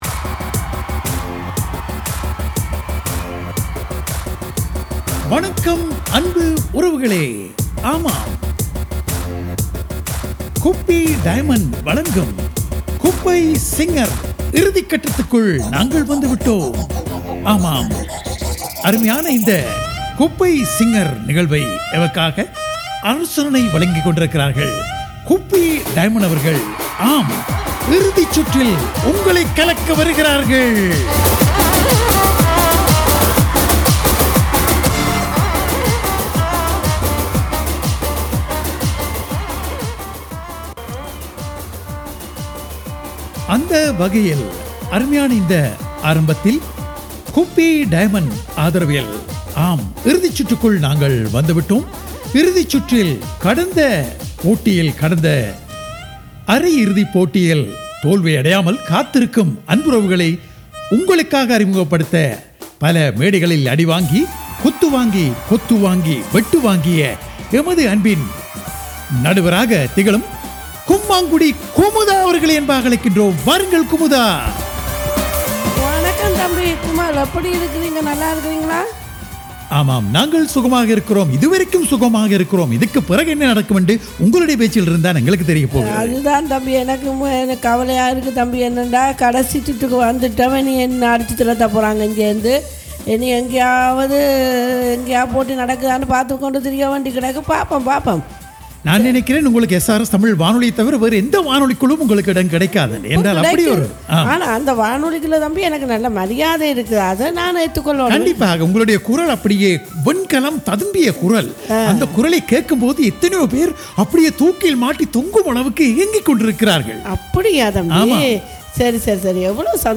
SRS தமிழ் வானொலியின் நகைச்சுவைச்சித்திரம் கும்பகோணம் குப்பை சிங்கர் 01 இறுதிச்சுற்று 13/04/25